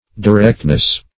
Directness \Di*rect"ness\, n.